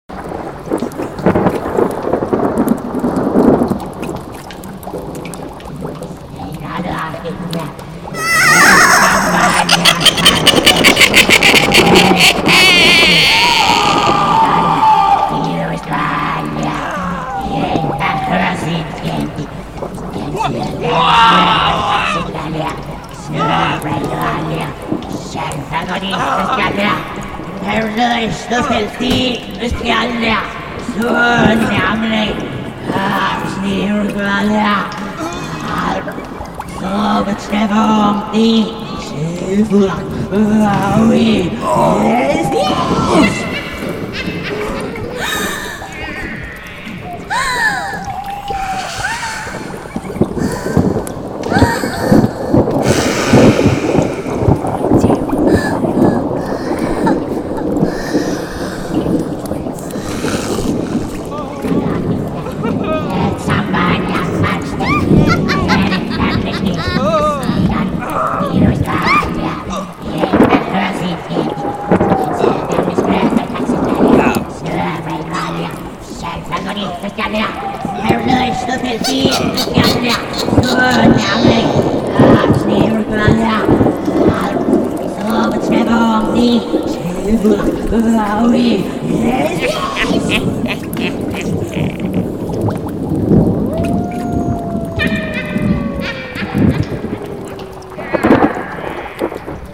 Witches' Brew of Horror Mix Sound Effects
boiling brew bubbling cauldron chant chanting crack death sound effect free sound royalty free Sound Effects